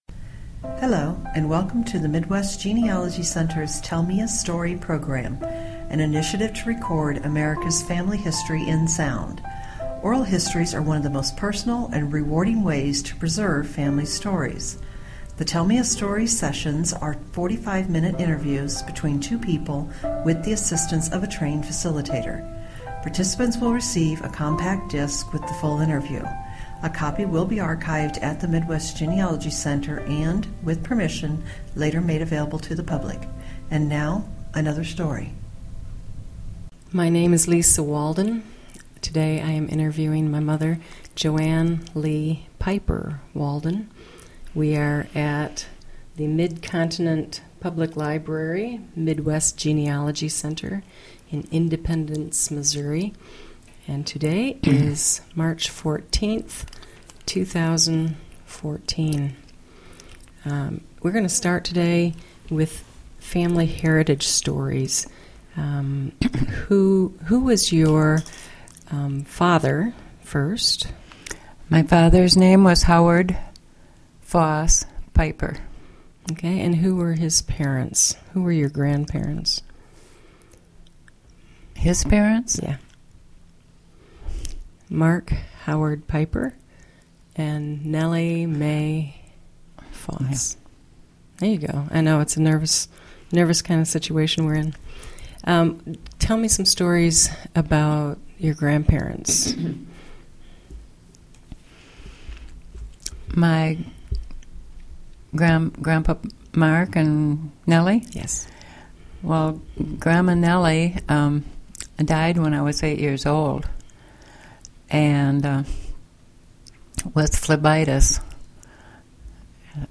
Genealogy Family history Oral history